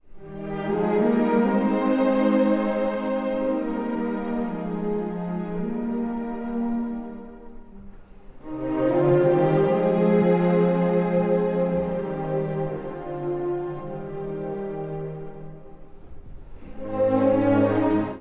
↑古い録音のため聴きづらいかもしれません！（以下同様）
ヴィソカーの情景がわっと頭に広がる、雄大な旋律です。
都会的ではなく、土の香りがするような温かさ。